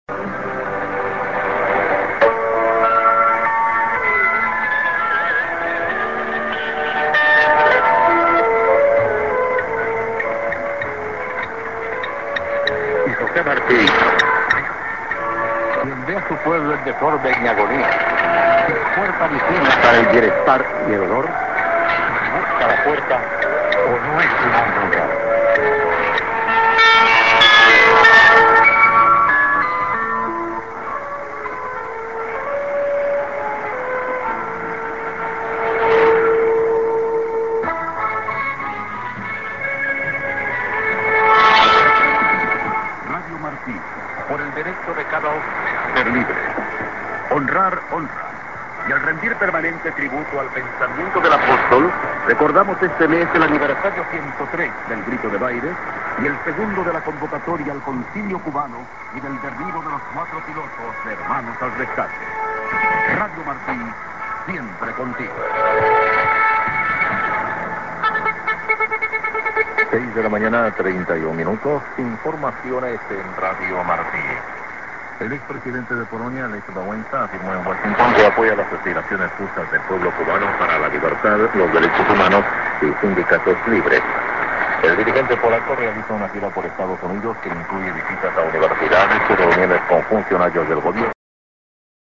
IS+ID(man)->ID+SKJ(man:Eng)->　＊Delano,Cali.